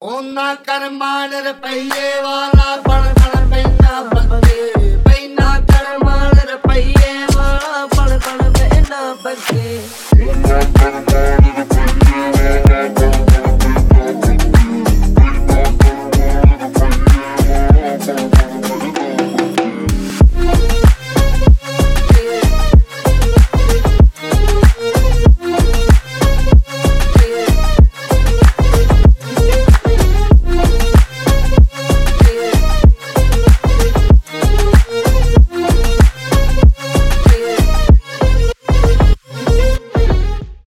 deep house , g-house , house , клубные